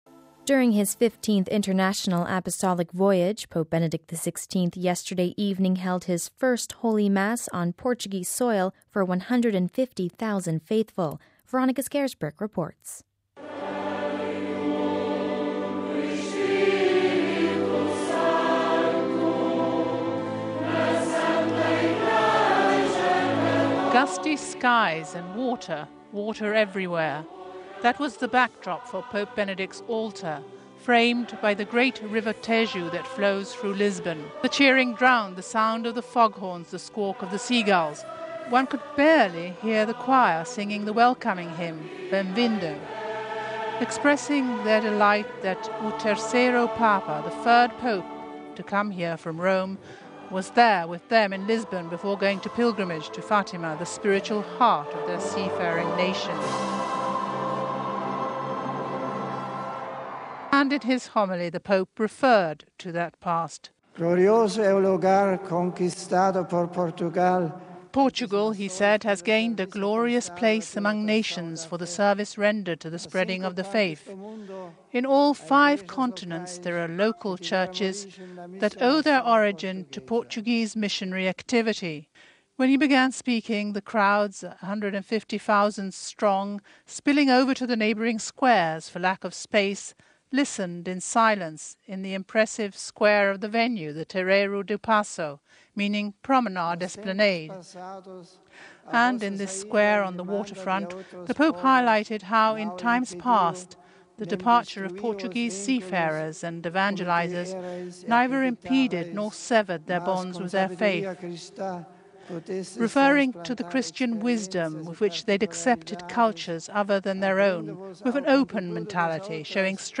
And the cheering drowned the sound of the fog horns , the squawk of the sea gulls .
One could barely hear the choir singing the welcoming hymn, “Bem vindo”.